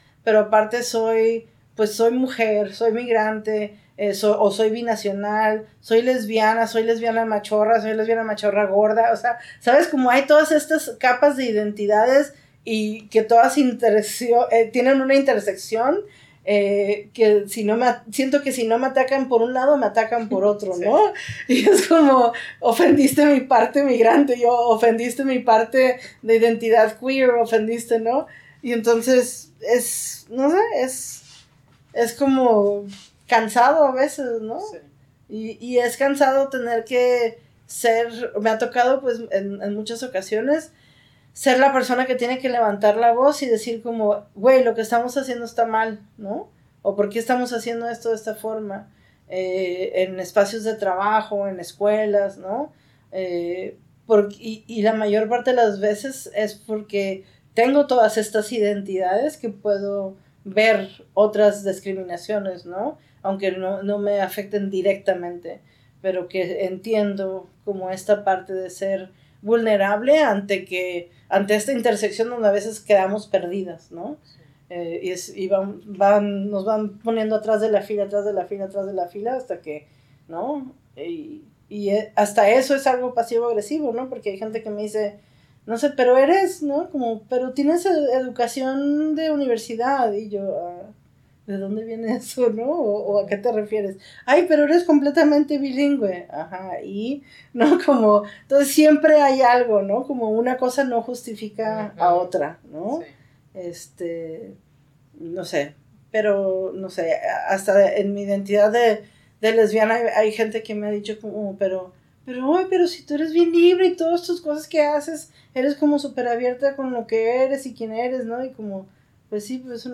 Segunda parte Entrevista